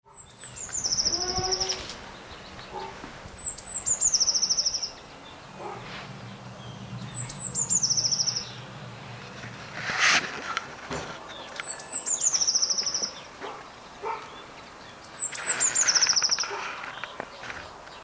Seznamte se: SÝKORA MODŘINKA